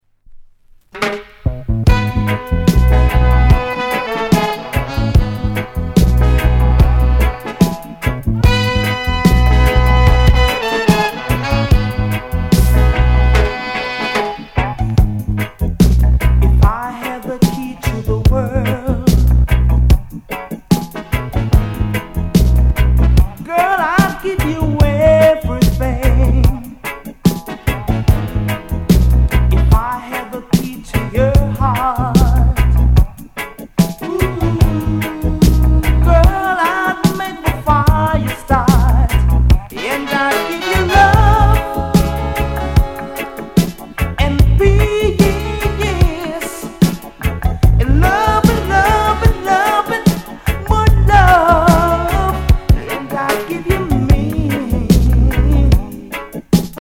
LOVER ROCK